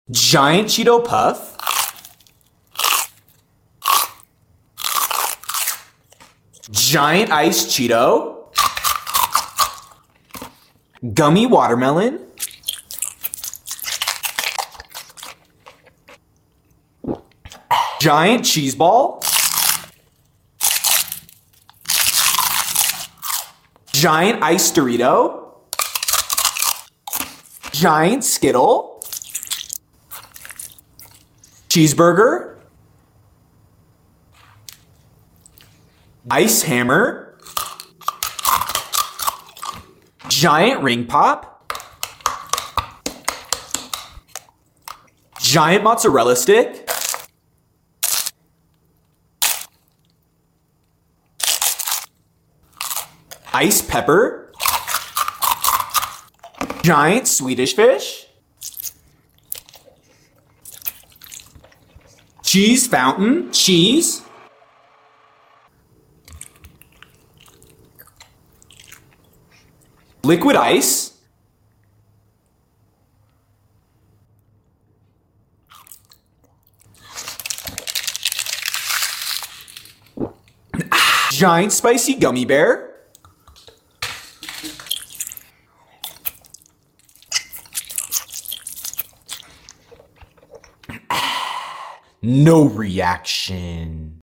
Giant Gummy Food Eating ASMR sound effects free download